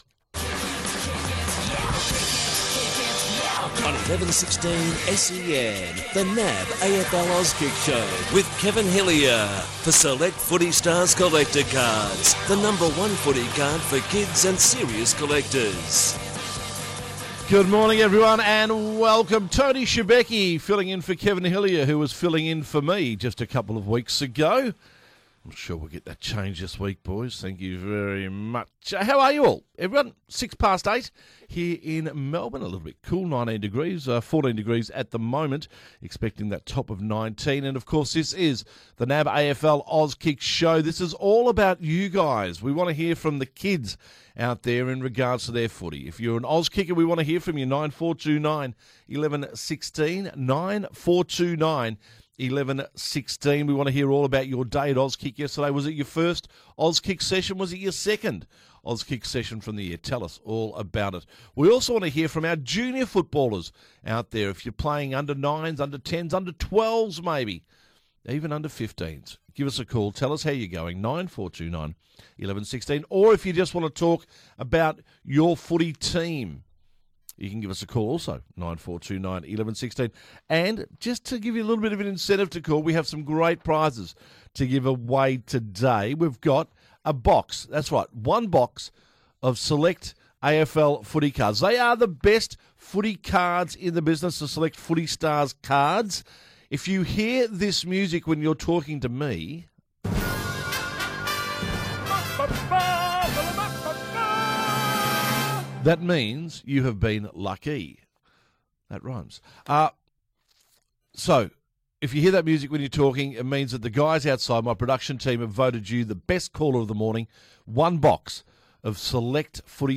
takes all your calls on The Auskick Show!